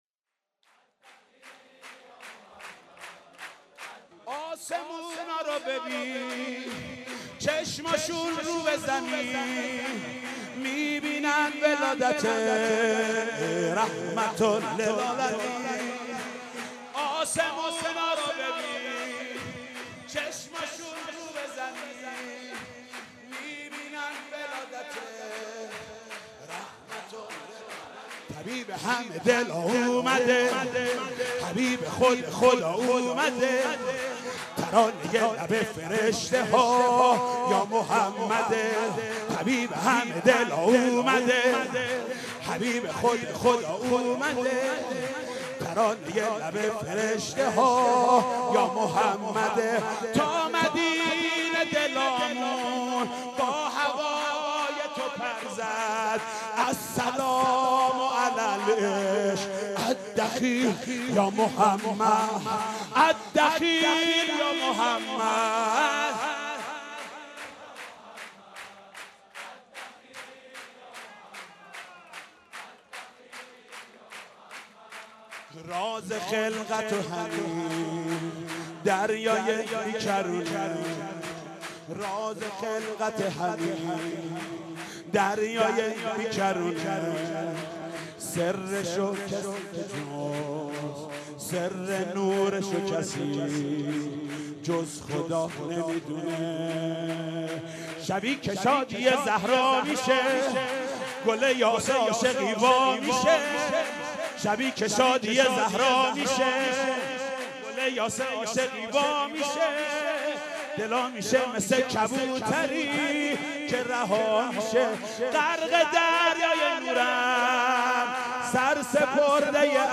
مداحی میلاد پیامبر (ص) و امام صادق(ع)